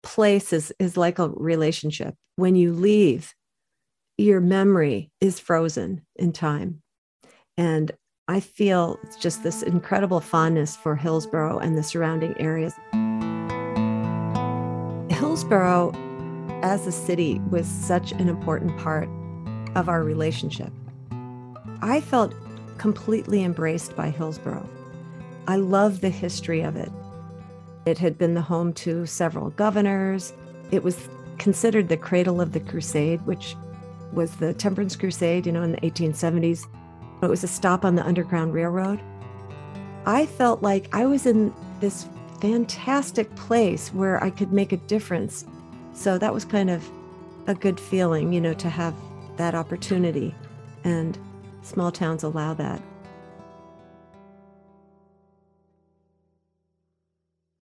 Interviews and audio